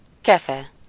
Ääntäminen
Synonyymit pinceau Ääntäminen France: IPA: /bʁɔs/ Haettu sana löytyi näillä lähdekielillä: ranska Käännös Ääninäyte Substantiivit 1. kefe Muut/tuntemattomat 2. ecset 3. seprű 4. kefehaj Suku: f .